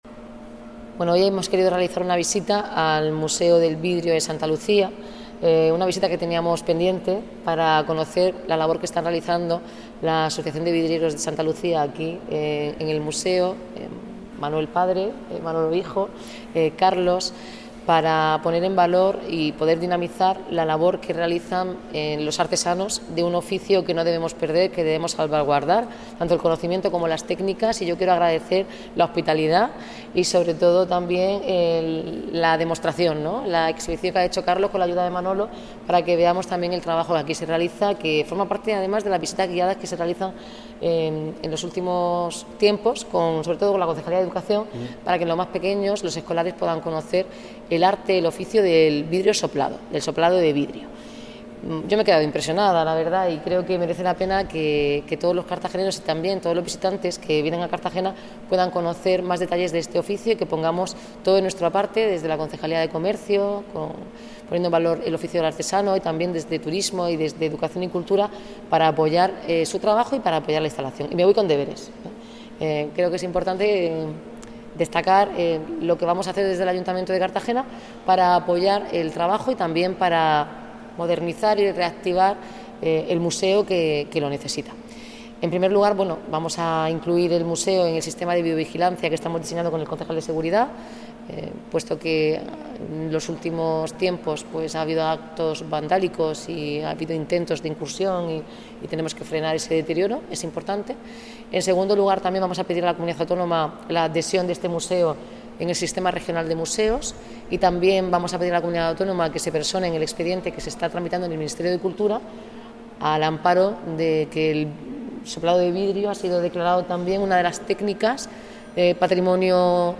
Declaraciones
en el Museo del Vidrio